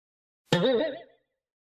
دانلود آهنگ تعجب از افکت صوتی انسان و موجودات زنده
دانلود صدای تعجب از ساعد نیوز با لینک مستقیم و کیفیت بالا
جلوه های صوتی